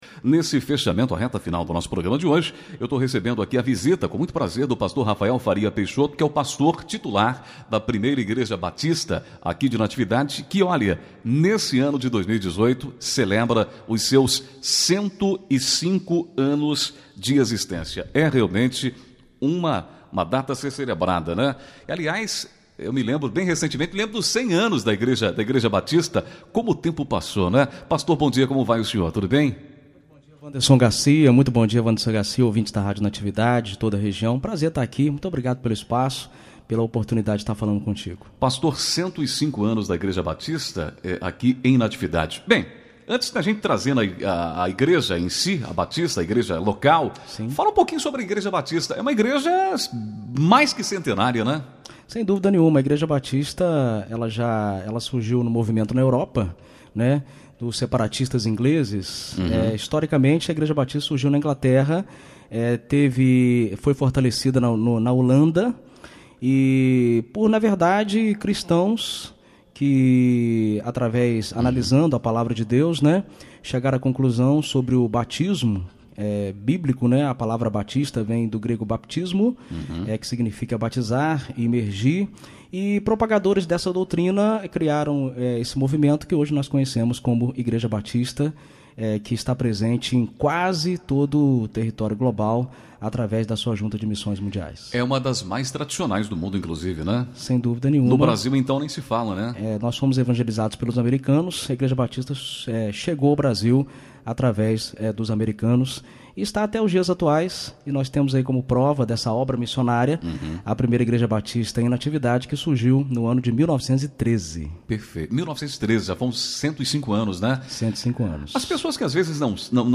26 julho, 2018 ENTREVISTAS, FaceLIVE, NATIVIDADE AGORA